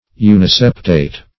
Search Result for " uniseptate" : The Collaborative International Dictionary of English v.0.48: Uniseptate \U`ni*sep"tate\, a. [Uni- + septate.]